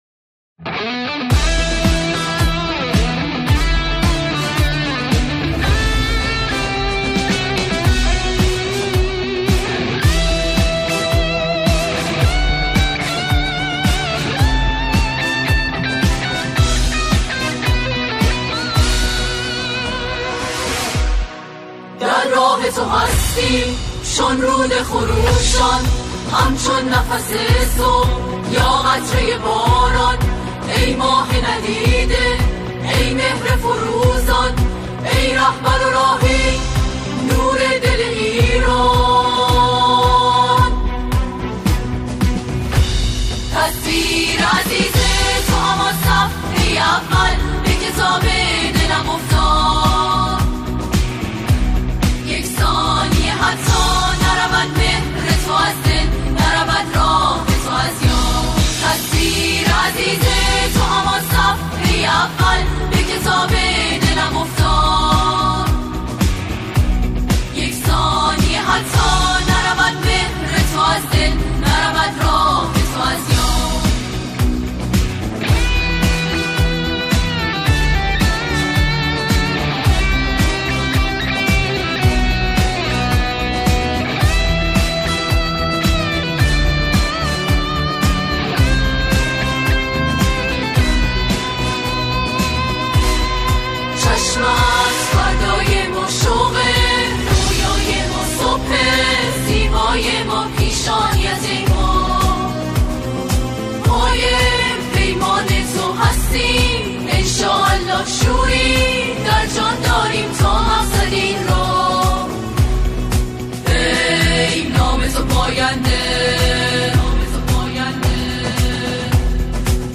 گروه کر اجرا می‌کنند.